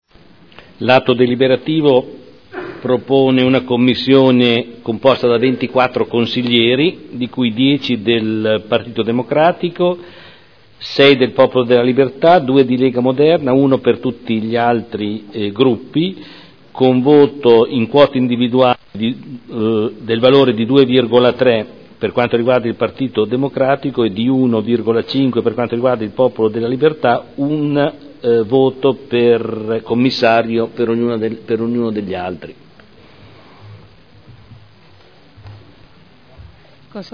Seduta del 12 dicembre Commissione consiliare permanente Servizi Culturali, Sociali, Educativi, Sport e Tempo libero – Modifica